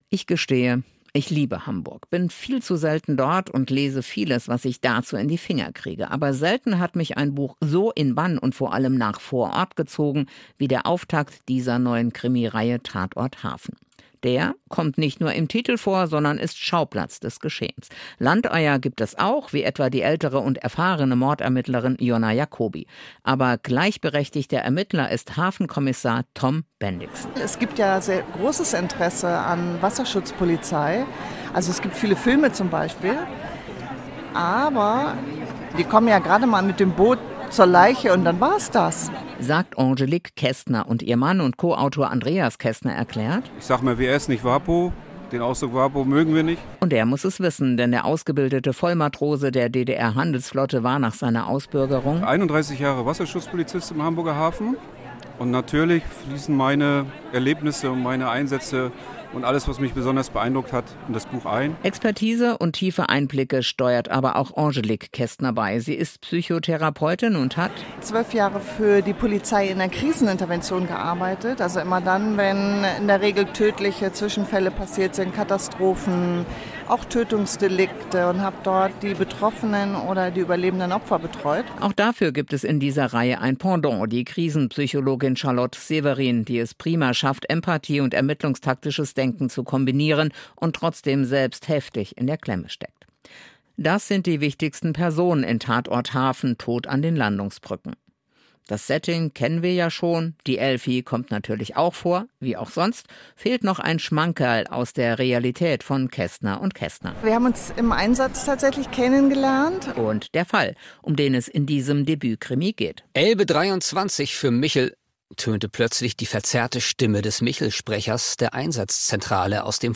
Krimitipp im SR 3 Radio
Der Radiosender SR3 gibt regelmässig Krimitipps. Am 13. Juli 2024 wurden die Hafenkrimis von Kästner&Kästner vorgestellt.